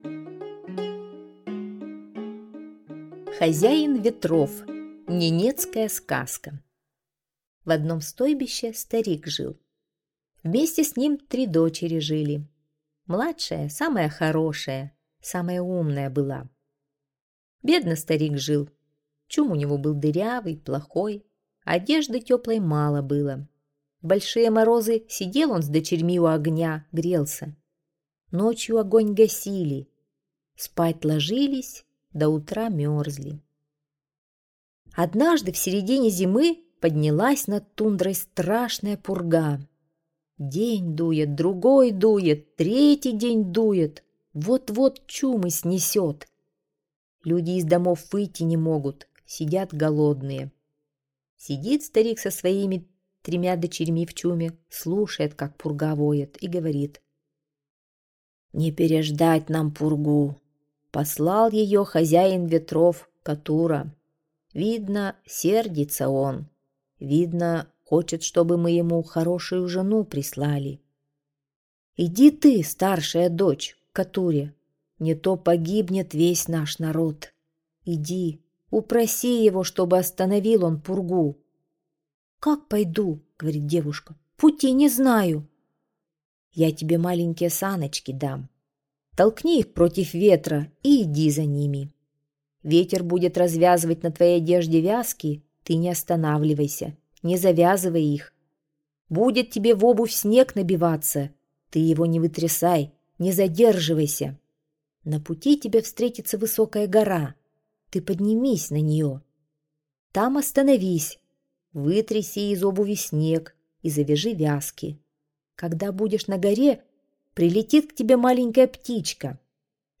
Хозяин ветров - ненецкая аудиосказка - слушать онлайн